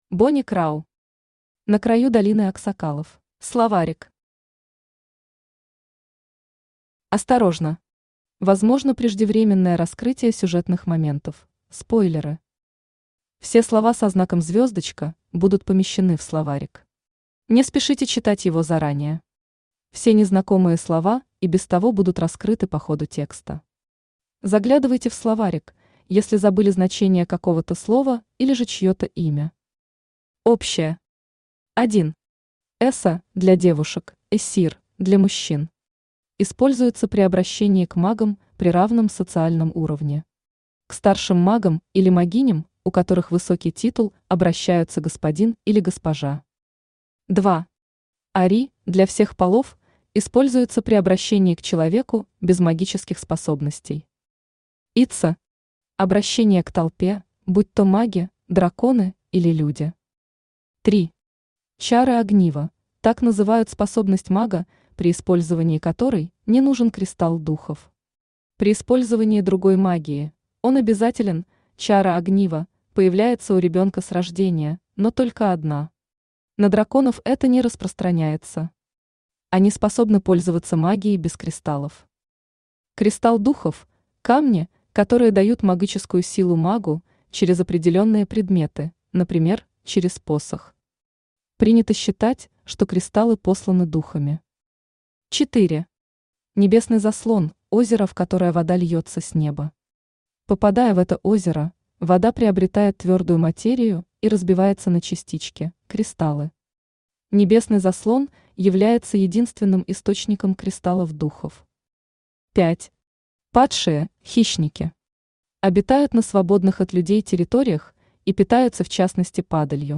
Аудиокнига На краю долины аксакалов | Библиотека аудиокниг
Aудиокнига На краю долины аксакалов Автор Бони Крау Читает аудиокнигу Авточтец ЛитРес.